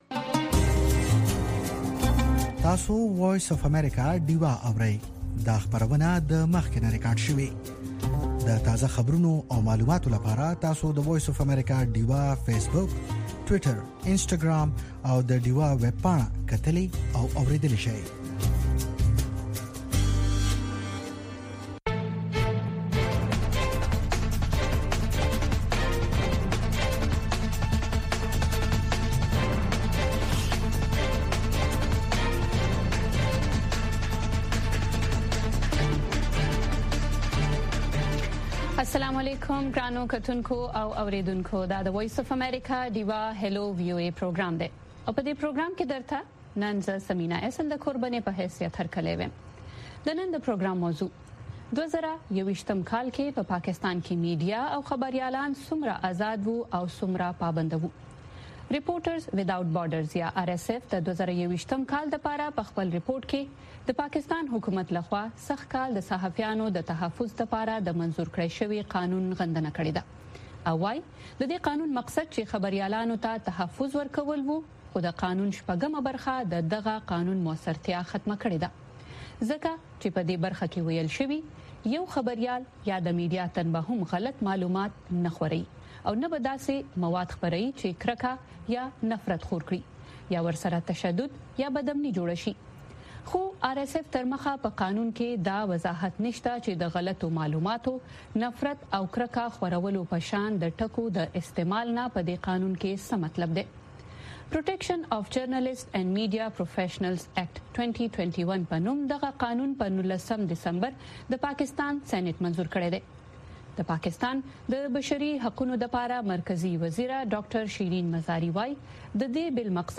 په دې خپرونه کې اوریدونکي کولی شي خپل شعر یا کوم پیغام نورو سره شریک کړي. د شپې ناوخته دا پروگرام د سټرو اوریدنکو لپاره ښائسته خبرې او سندرې هم لري.